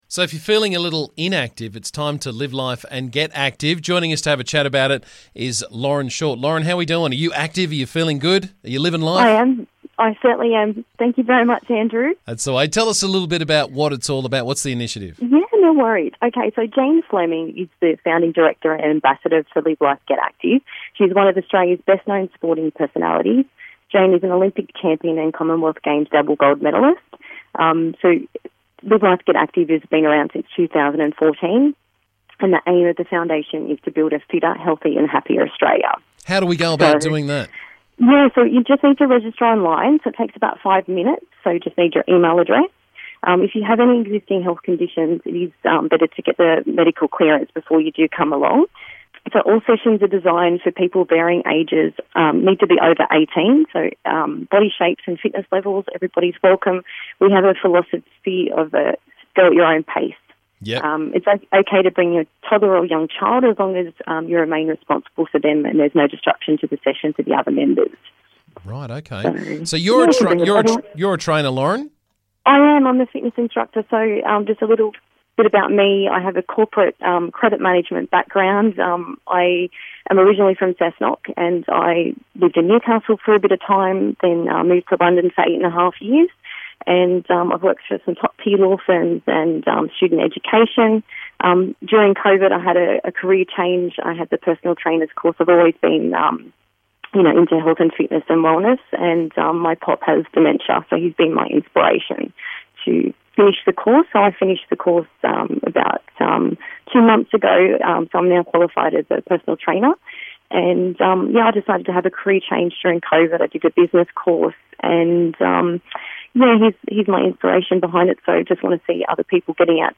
she was on the show to tell us all about 'Live Life Get Active'.